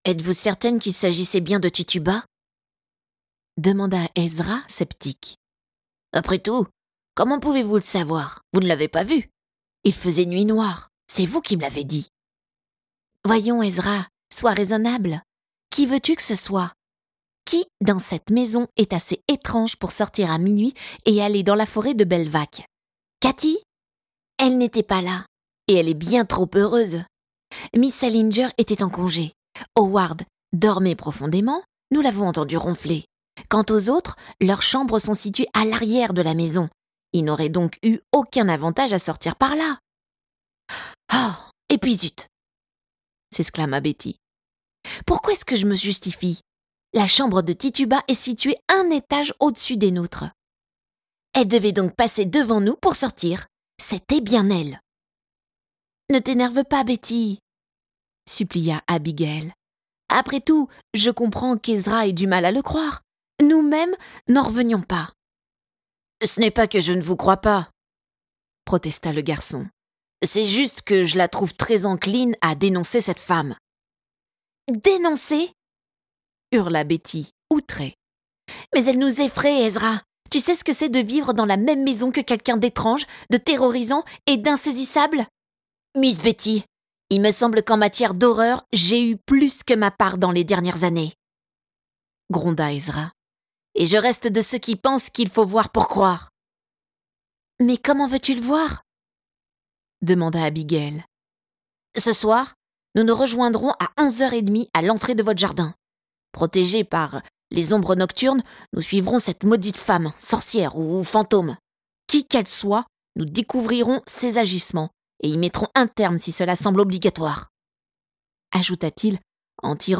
Extrait vidéo du livre audio